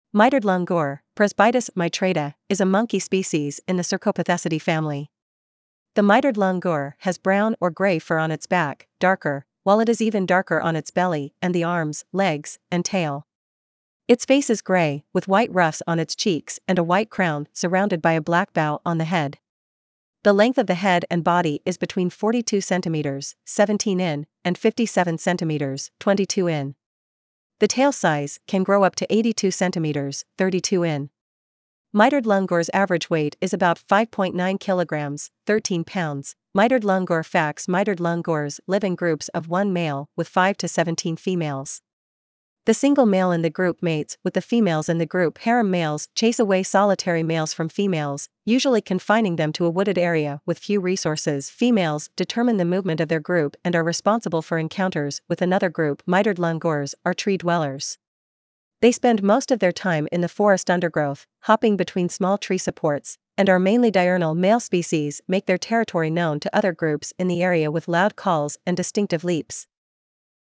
Mitered Langur
• Male species make their territory known to other groups in the area with loud calls and distinctive leaps.
Mitered-langur.mp3